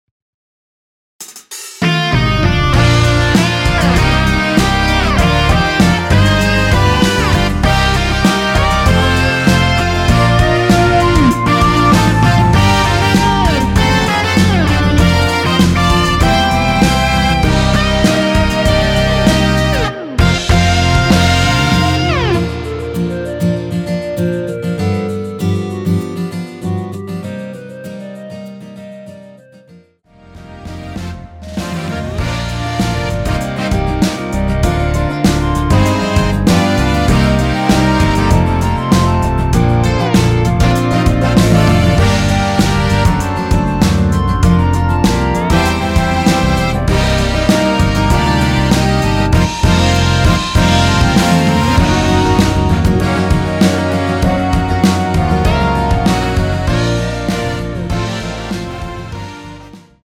원키에서(-2)내린 멜로디 포함된 MR입니다.(미리듣기 확인)
Bb
앞부분30초, 뒷부분30초씩 편집해서 올려 드리고 있습니다.
중간에 음이 끈어지고 다시 나오는 이유는